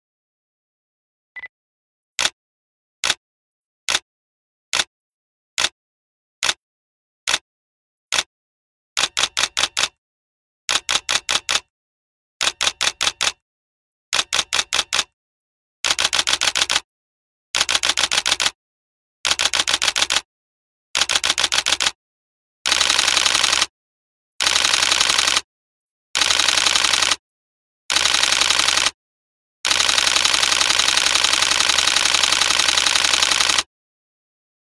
…il suono dell’allarme è la riproduzione del suono di scatto della D4.
D4_shutter_alarm.mp3